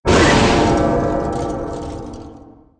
playerdies_3.ogg